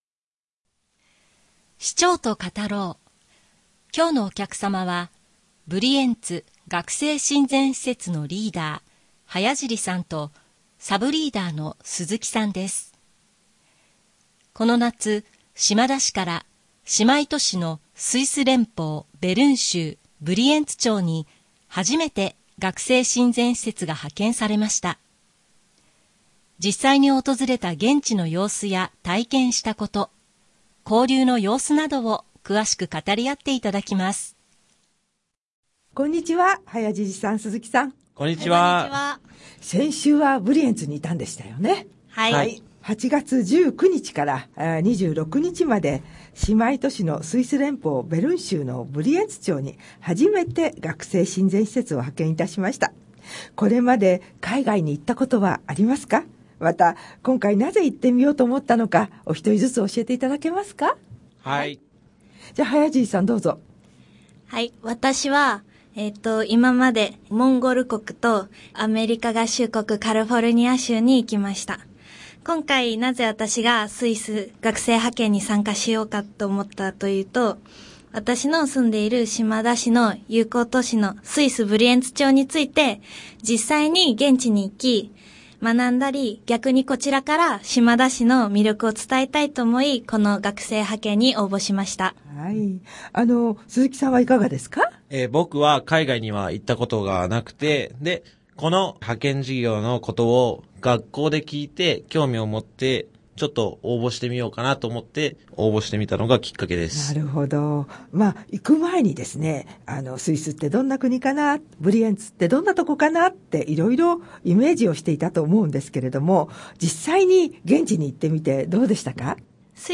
毎月1回、市民をお招きし、注目度や関心度の高い話題をテーマに、市長と対談形式でラジオ放送をお送りします。